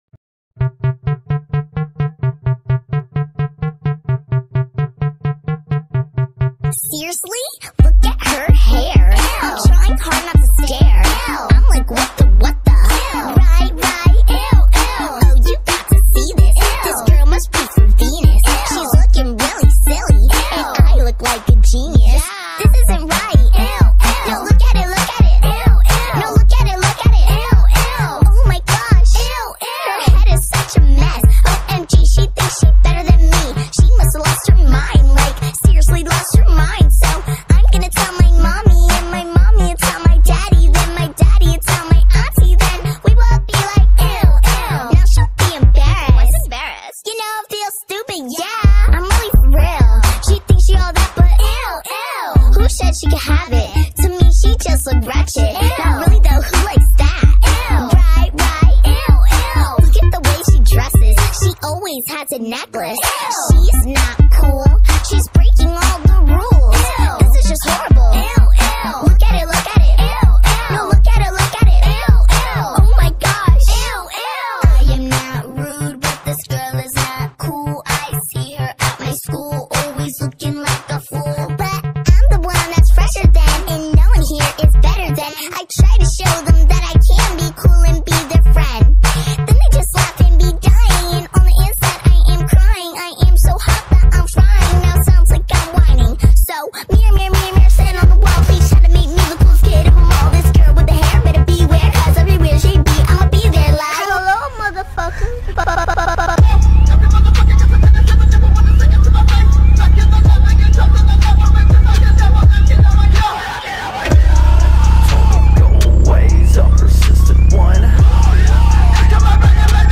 • Качество: 320 kbps, Stereo
Remix) (Bass Boosted